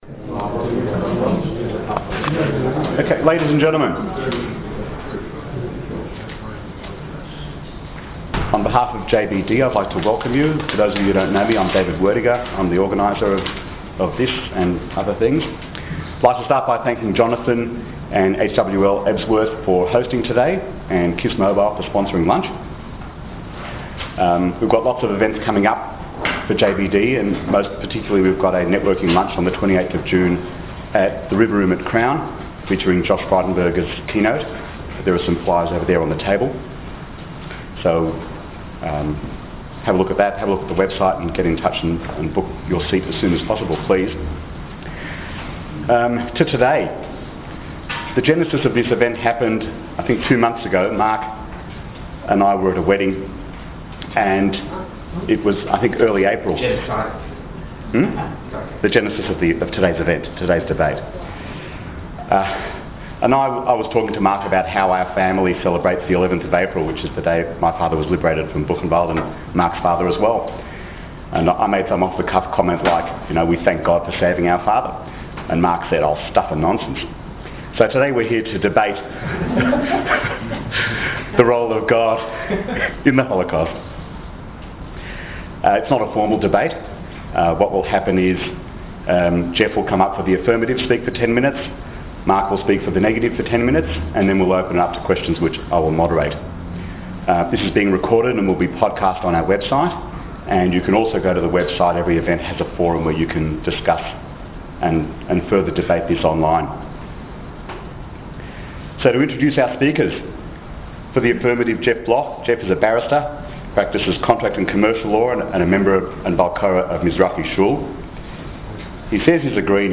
JBD are pleased to invite you to a lunchtime debate on the widely discussed topic